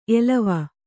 ♪ elava